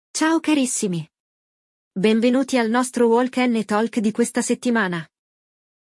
Il dialogo